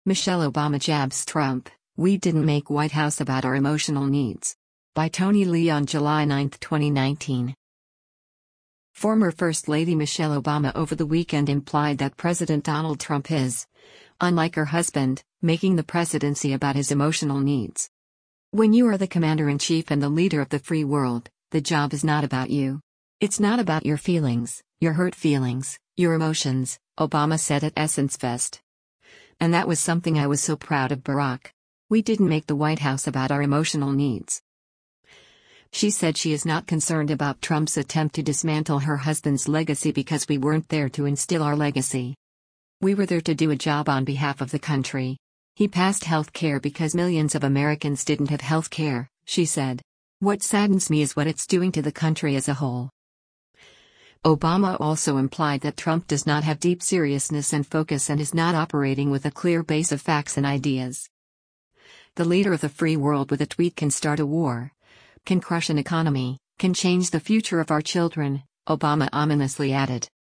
Michelle Obama and Gayle King seen at the 2019 Essence Festival at the Mercedes-Benz Super